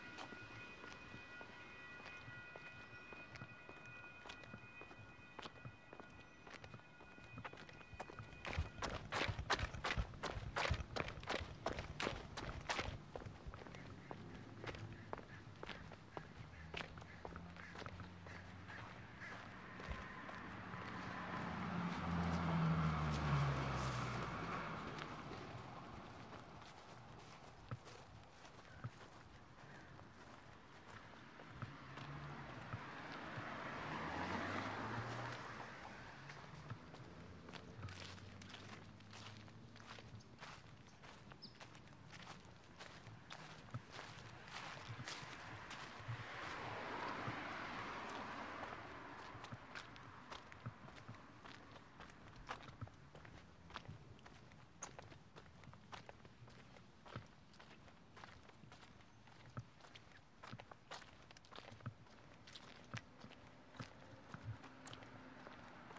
描述：男人走路
标签： 脚步声 男子
声道立体声